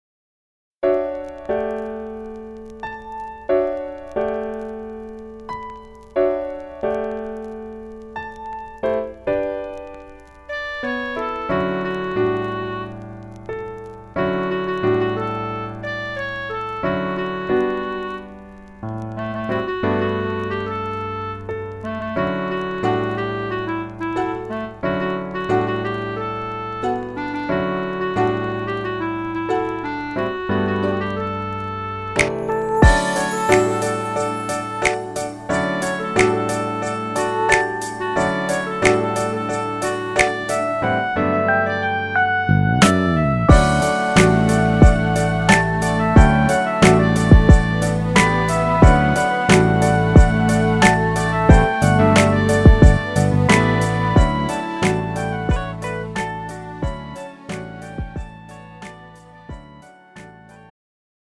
음정 원키 3:37
장르 가요 구분 Pro MR